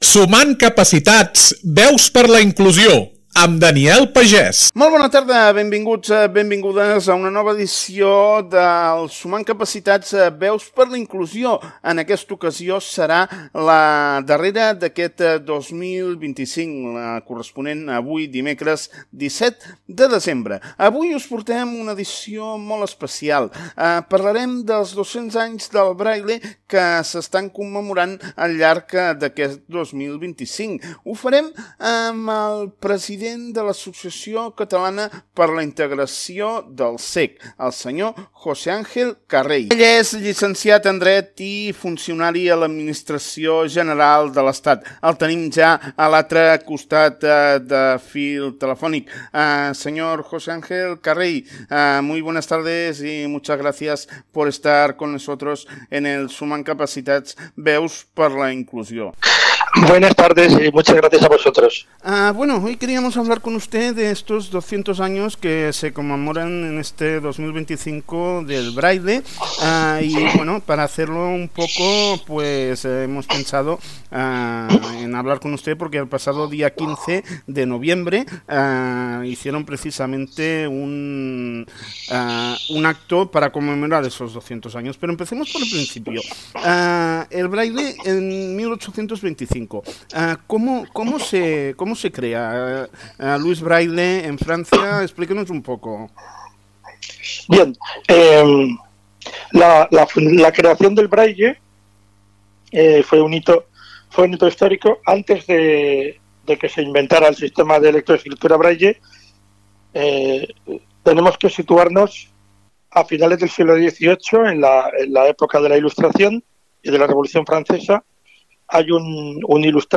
Un espai d’entrevistes sobre el món de la discapacitat, la inclusió i el Tercer Sector.